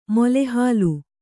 ♪ mole hālu